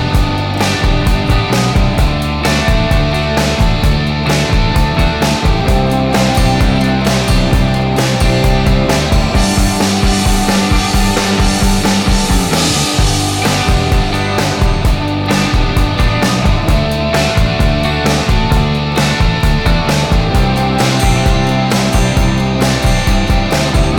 no Backing Vocals Indie / Alternative 4:13 Buy £1.50